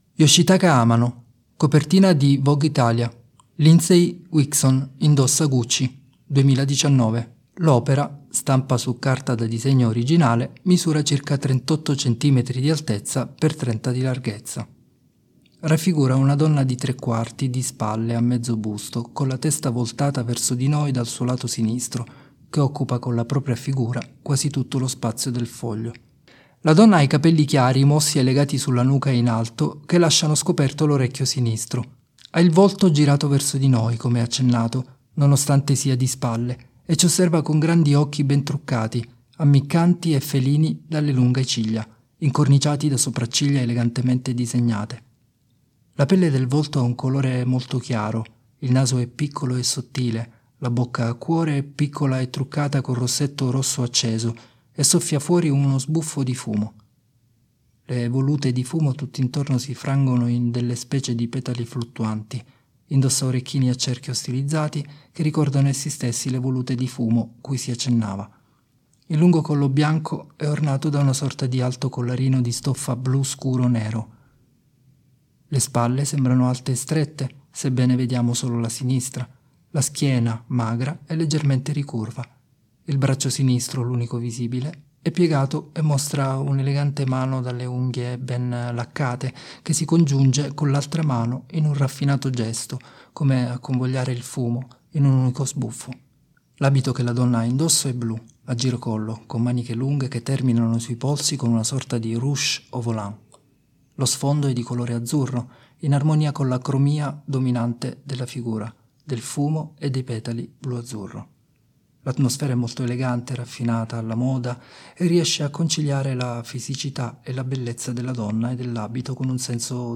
Descrizioni pannelli sensoriali per ciechi e ipo-vedenti: